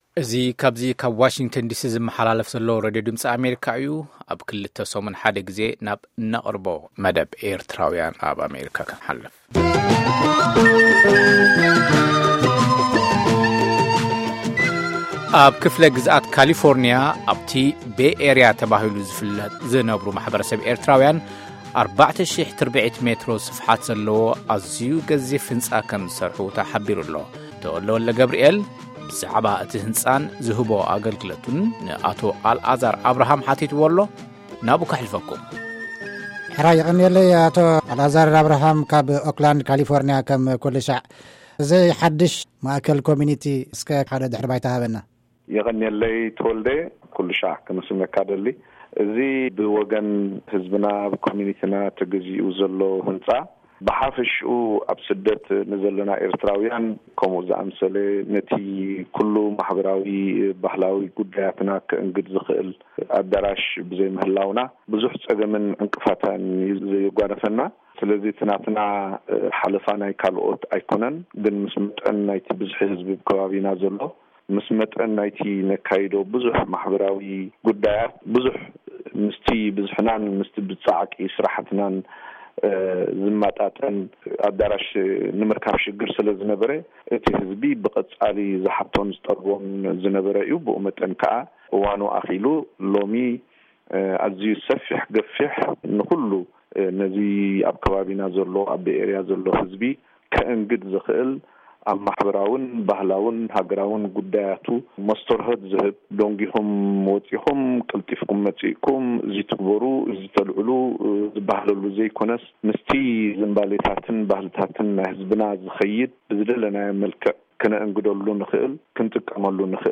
ቃለ-መጠይቕ